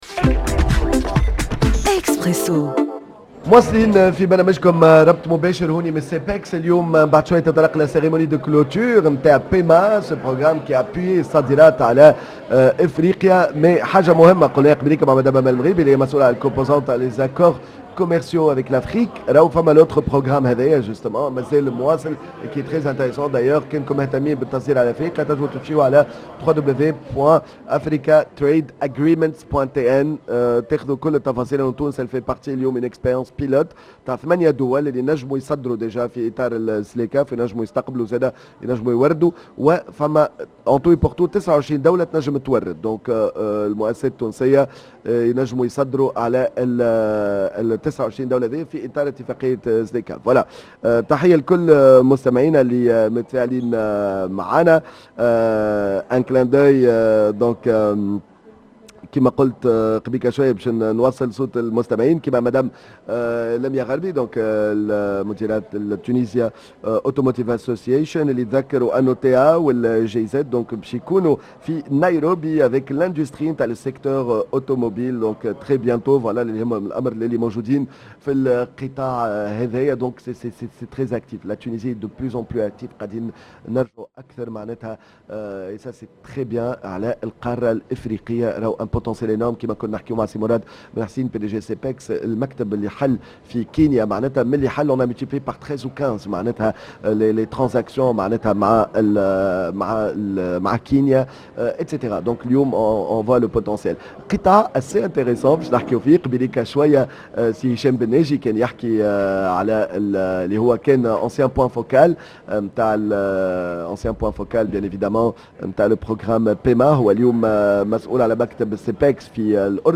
dans un plateau spécial en direct du CEPEX